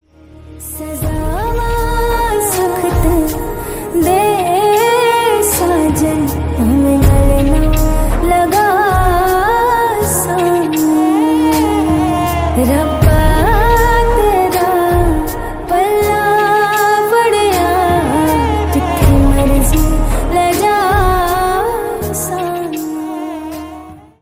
Punjabi sang